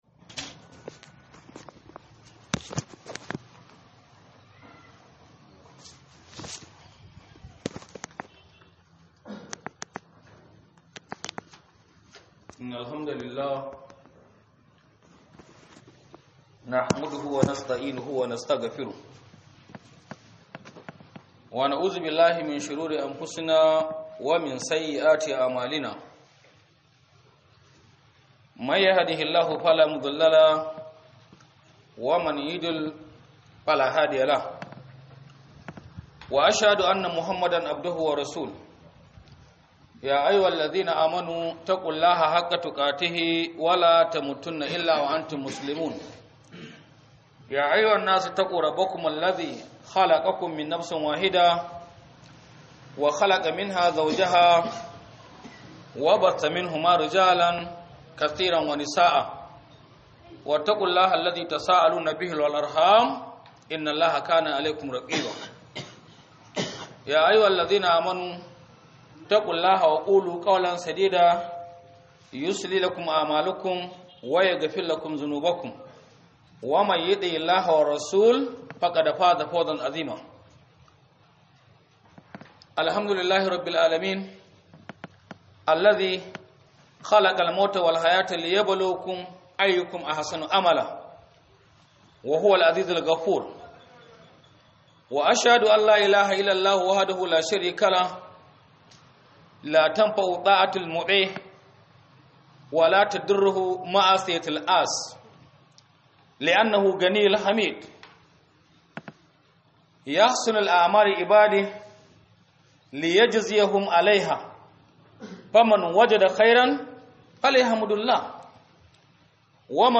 Khudubar Sallar Juma'a